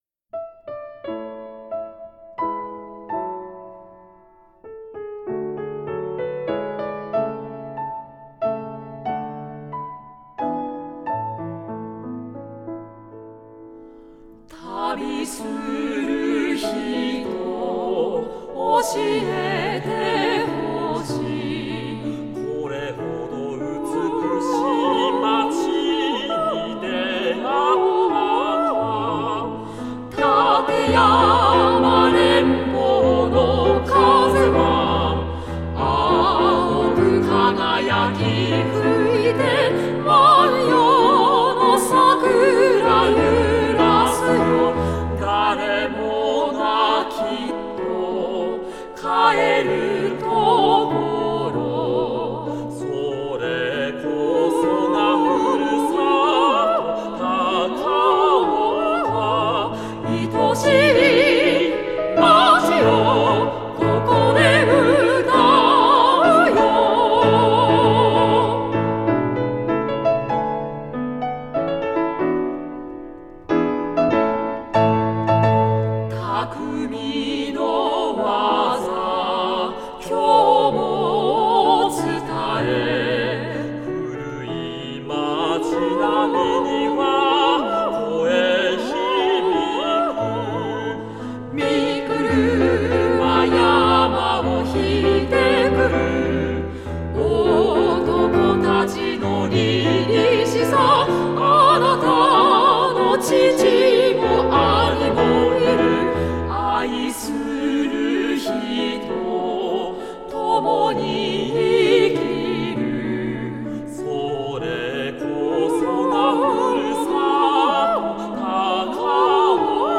イ長調）混声三部合唱、ピアノ伴奏